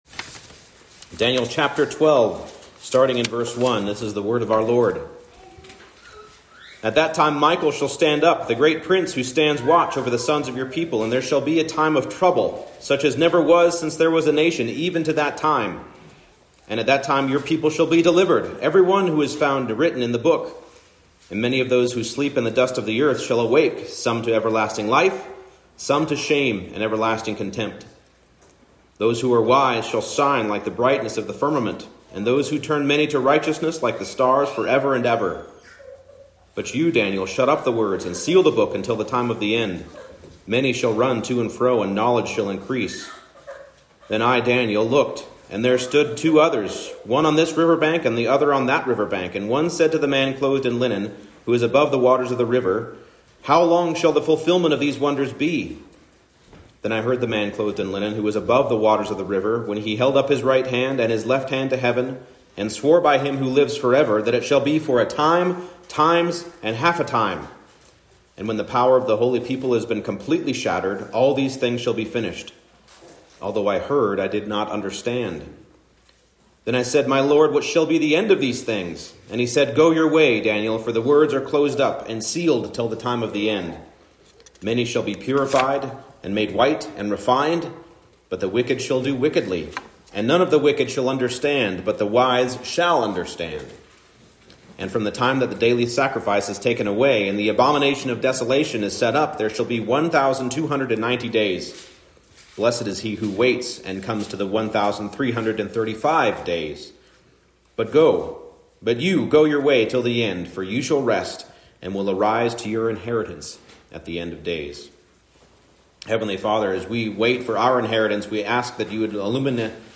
Daniel 12:1-13 Service Type: Morning Service The time before the end will be long and will be a period of tribulation for the purifying of God’s people.